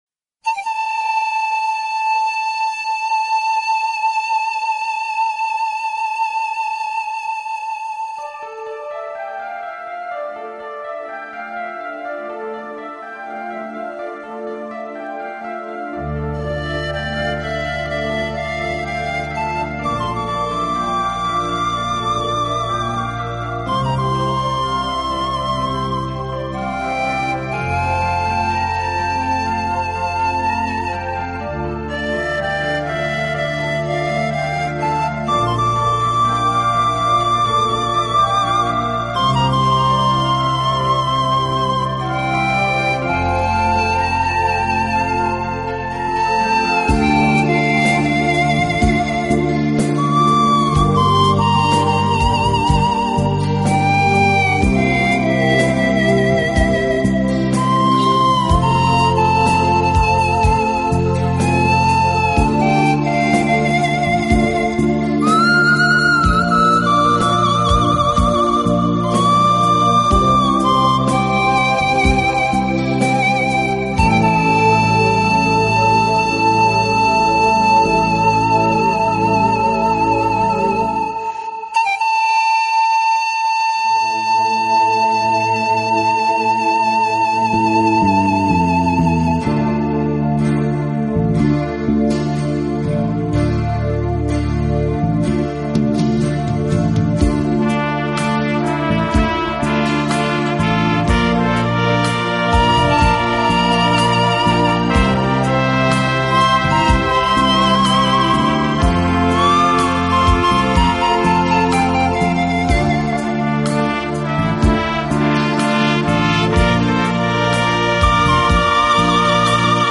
音乐类型：Panpipes (Pan Flute) | Instrumental